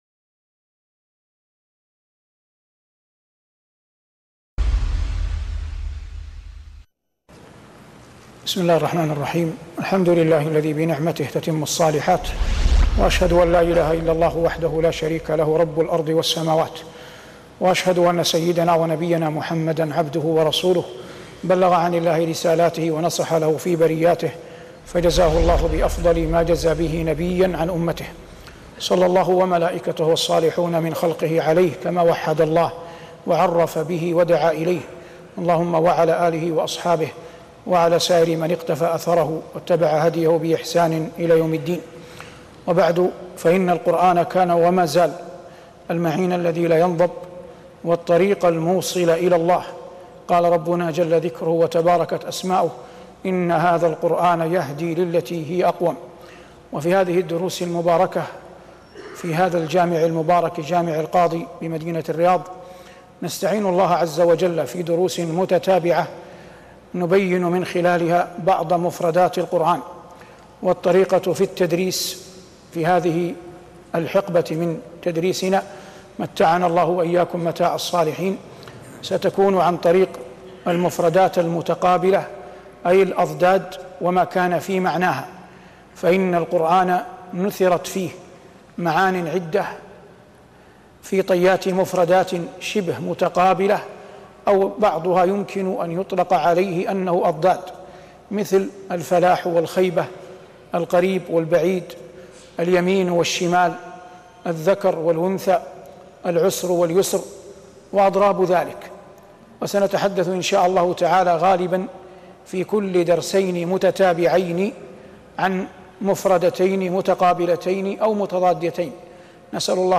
شبكة المعرفة الإسلامية | الدروس | الفلاح والخيبة-1 |صالح بن عواد المغامسي